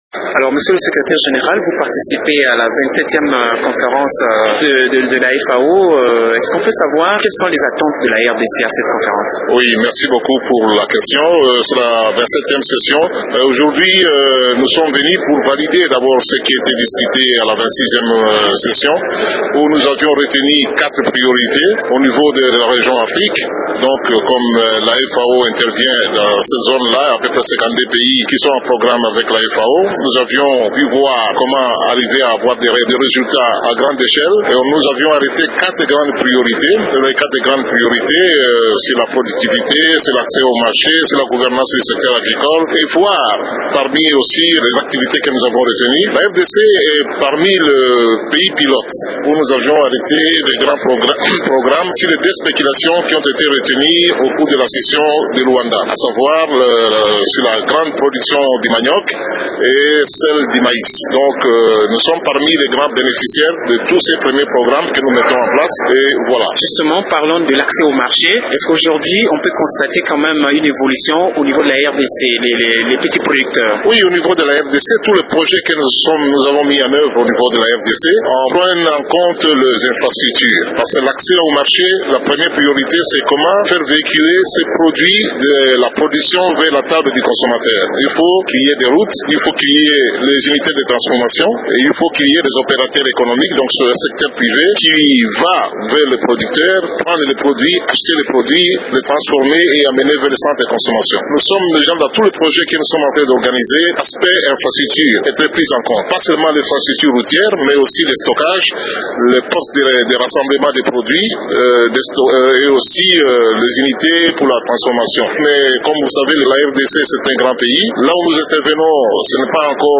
Ali Ramazani, Secrétaire général du ministère de l’Agriculture de la RDC participe à ces travaux. Dans cet entretien, il évoque les efforts de la RDC pour mettre fin à l’insécurité alimentaire.